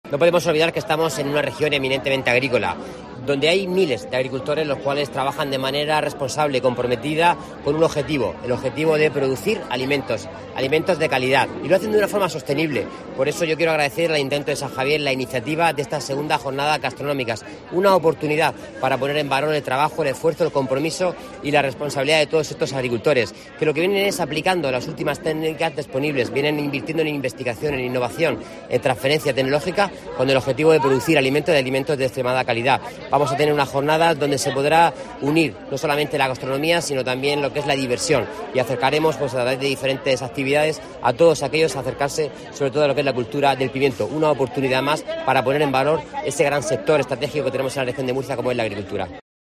Durante la Jornada Gastronómica de San Javier
Antonio Luengo, consejero de Agua, Agricultura, Ganadería y Pesca